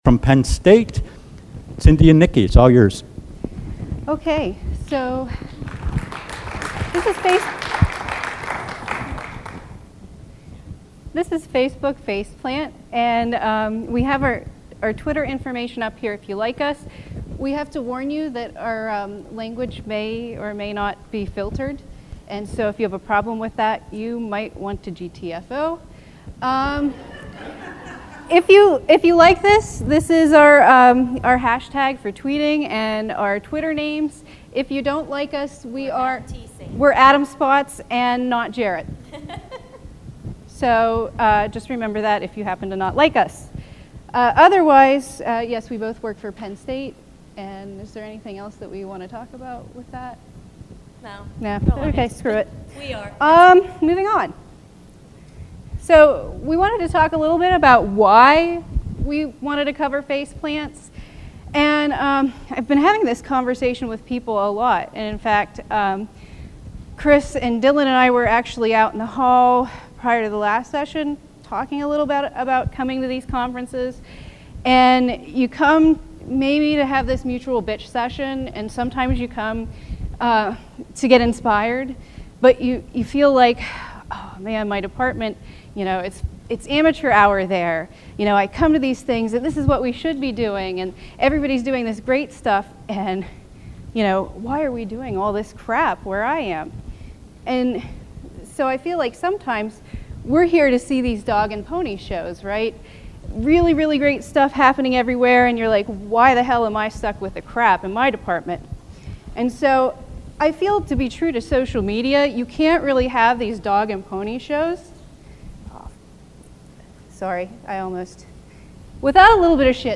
Continental Ballroom, Mezzanine Level